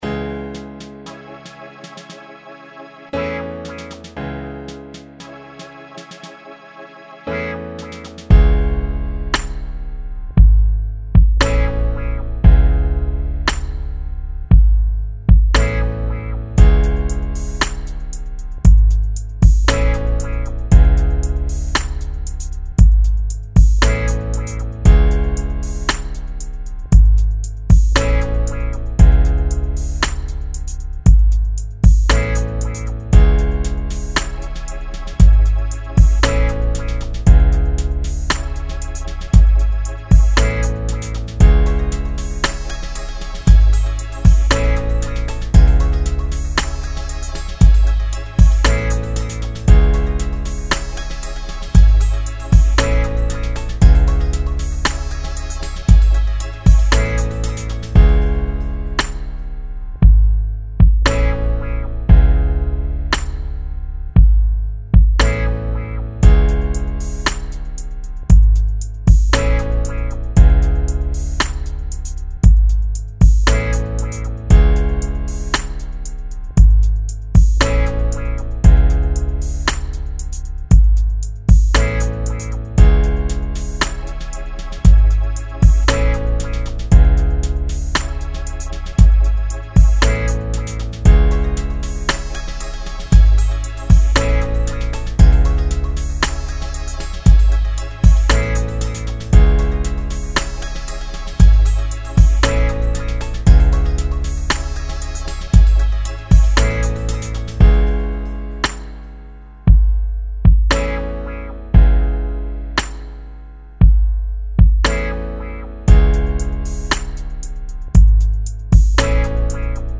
Epic beat, a lot of horns, strings, and choirs.
89 BPM.